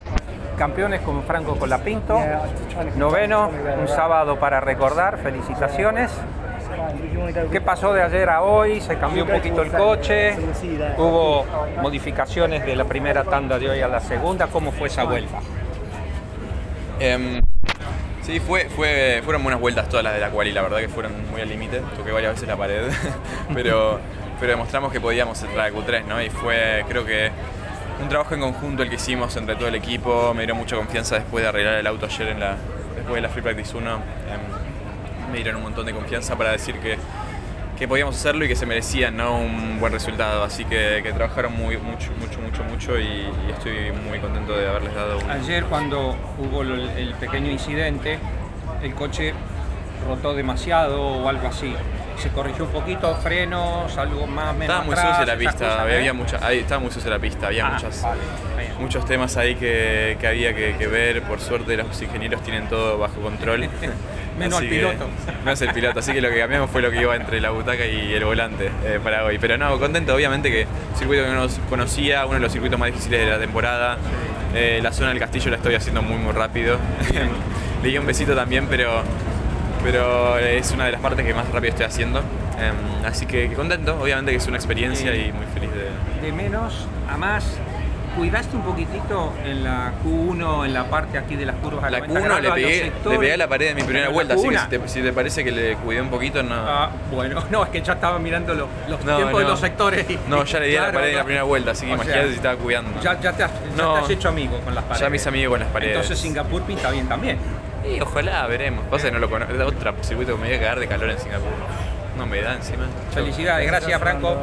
ESCUCHÁ LA PALABRA DE FRANCO COLAPINTO TRAS CLASIFICAR NOVENO EN AZERBAIJAN